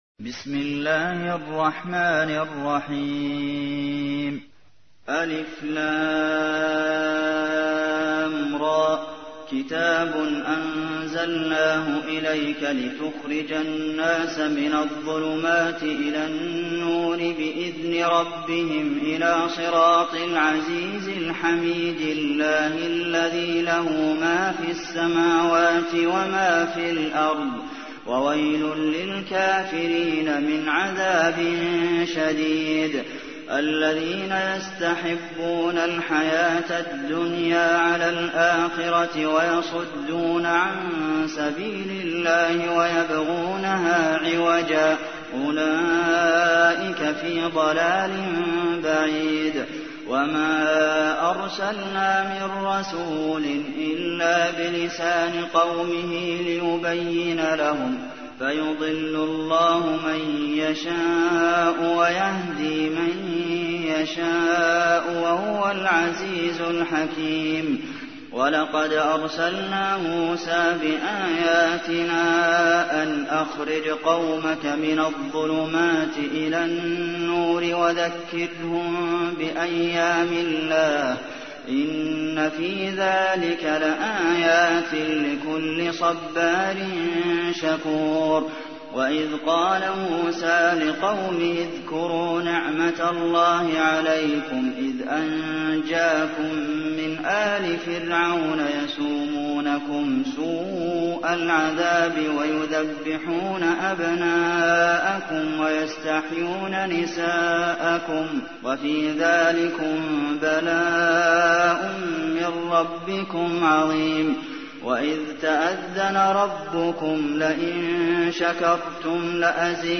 تحميل : 14. سورة إبراهيم / القارئ عبد المحسن قاسم / القرآن الكريم / موقع يا حسين